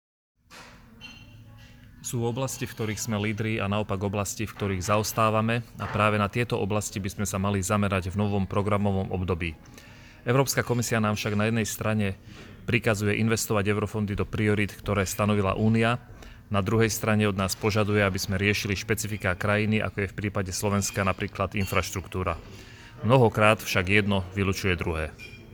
Richard-Raši-na-ministerskej-konferencii-Priatelia-kohézie-v-Dubrovníku.mp3